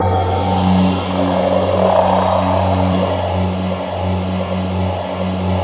SI1 BAMBO02L.wav